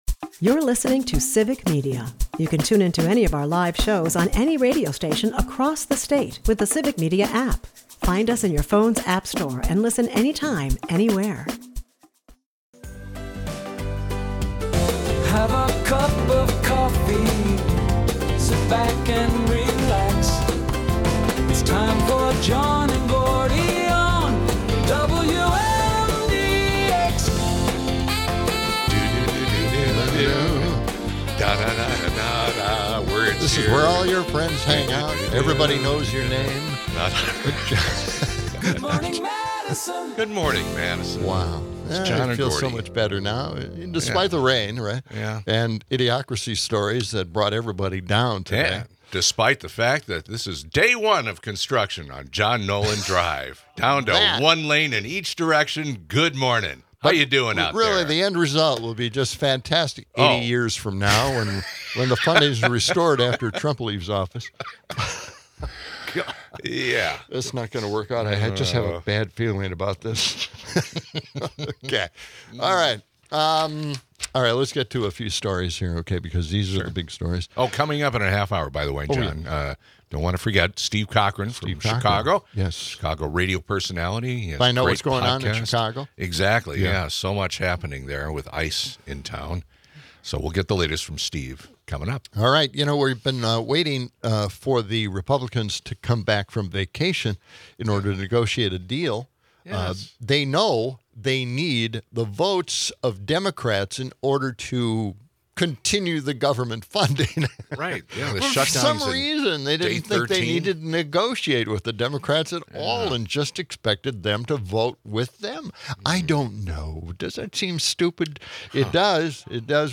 Spirited calls keep the energy high, while the upcoming Brewers game offers a dash of local pride.